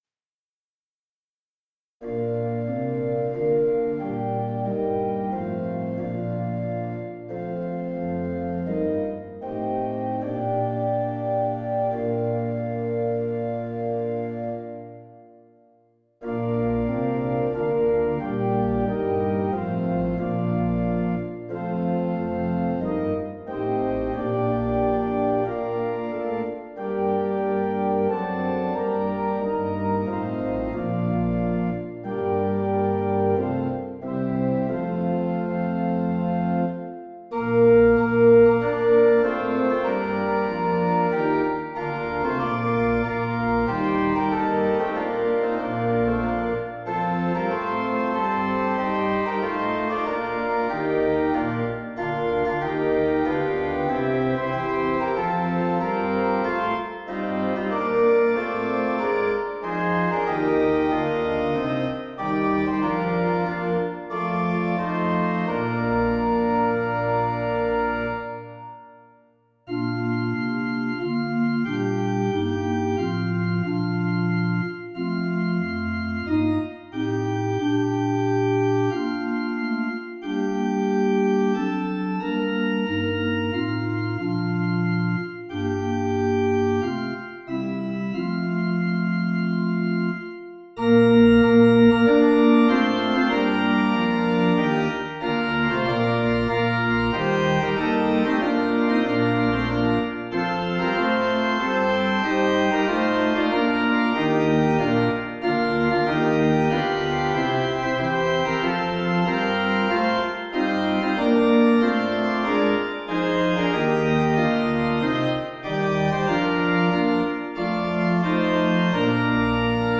Original Key B FLat Major